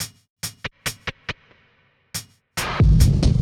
drum_r2.wav